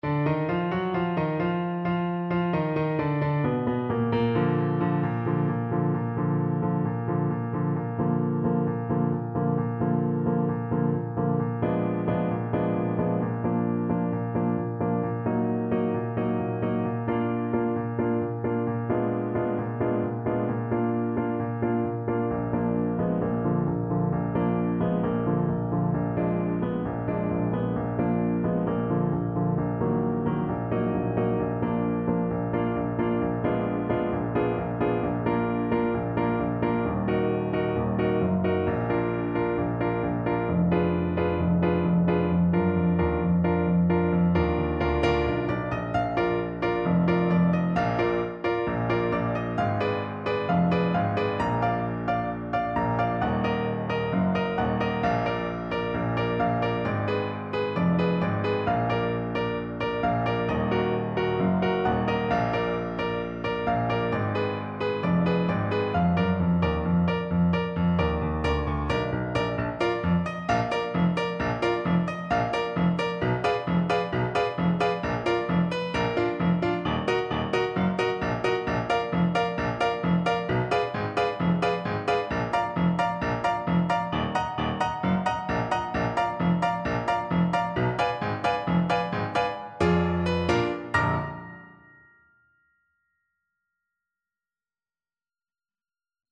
Molto allegro =c.132
4/4 (View more 4/4 Music)
world (View more world Clarinet Music)